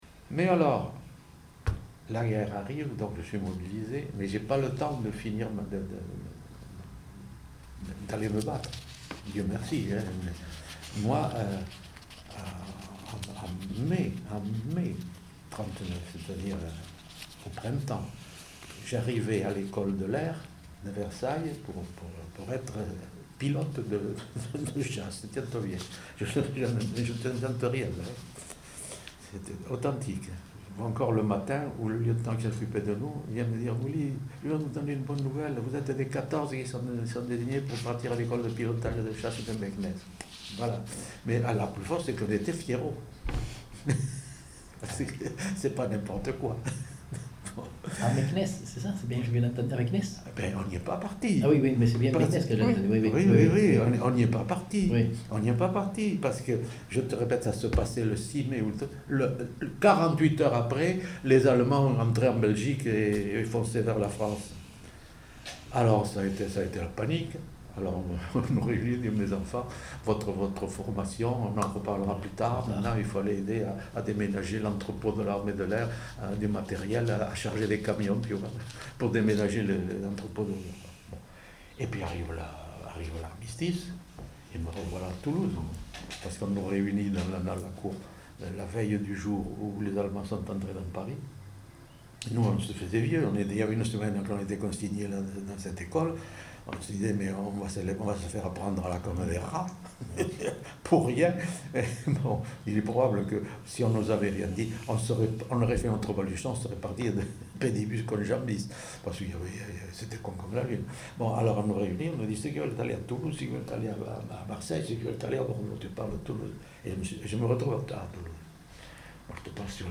Lieu : Saint-Sauveur
Genre : récit de vie